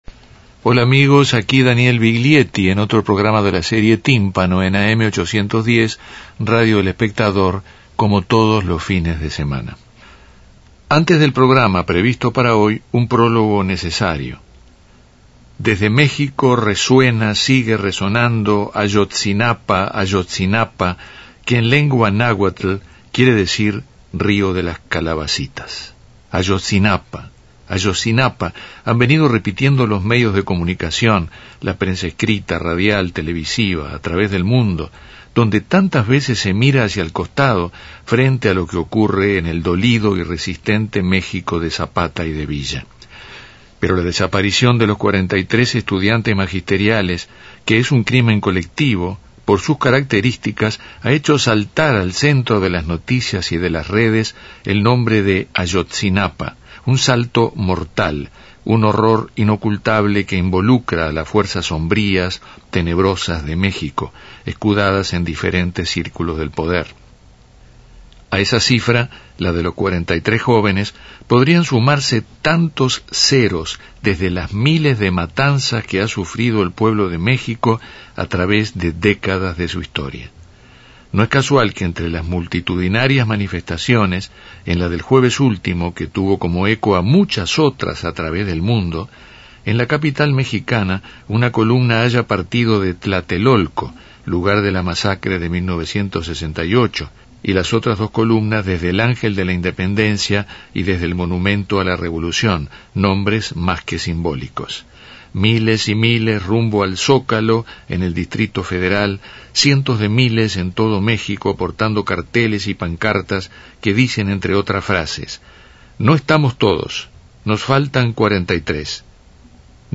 Encuentro timpanero con el popularísimo dúo uruguayo, que ya con más de tres décadas de trayectoria, Eduardo Larbanois y Mario Carrero, dialogan con Daniel Viglietti sobre sus trabajos en estos tiempos.
Entrevista